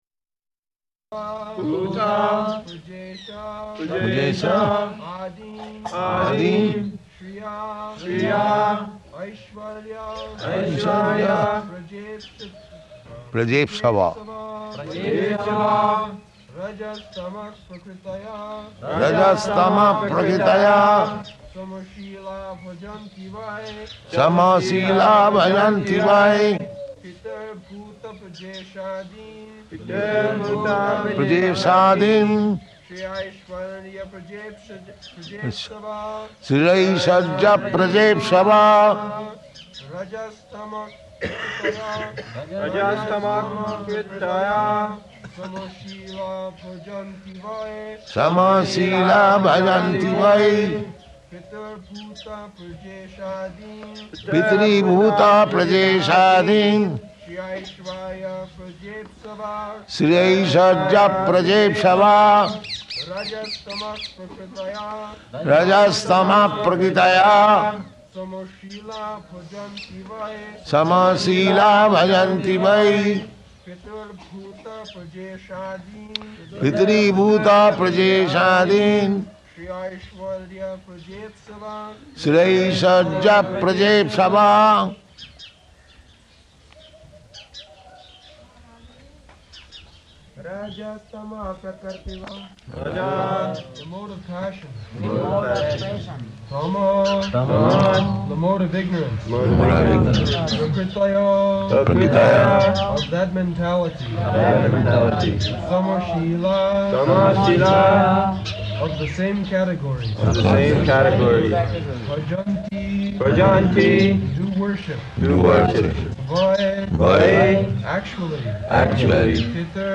November 7th 1972 Location: Vṛndāvana Audio file
[Prabhupāda and devotees repeat]